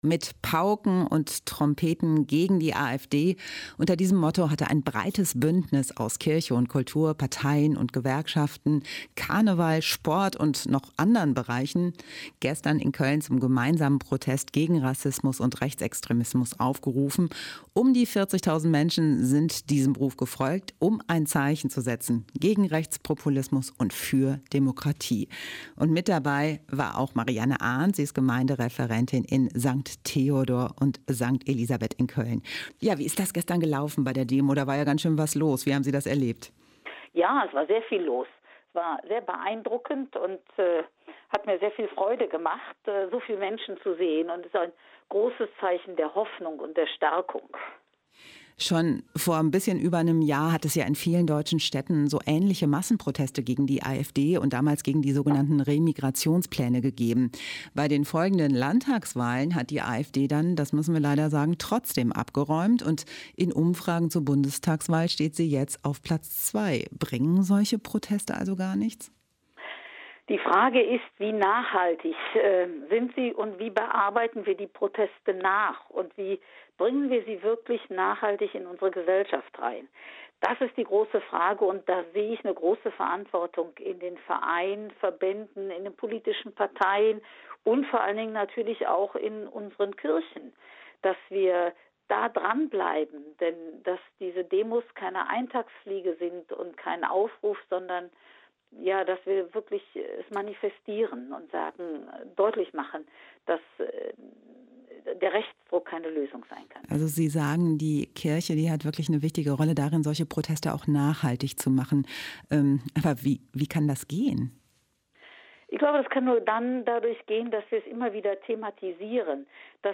Ein Interview